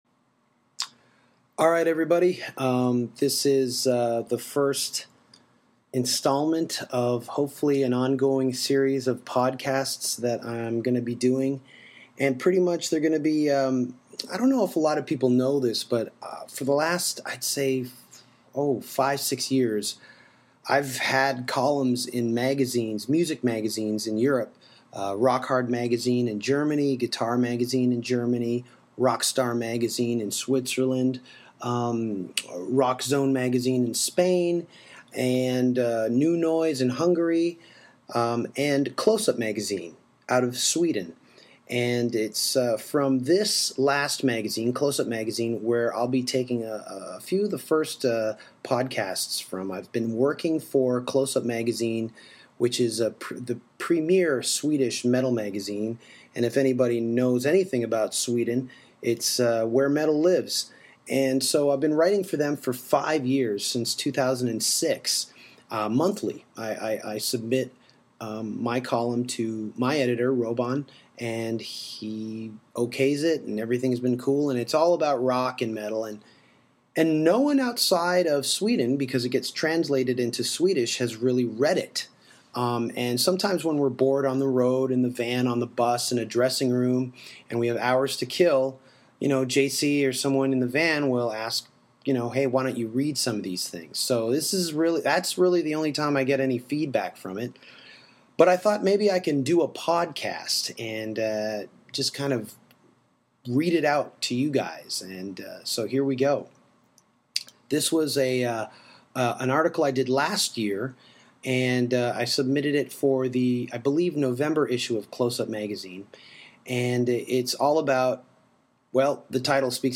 It’s simply me reading articles I’ve been writing in various Rock & Metal magazines for the last 6 years.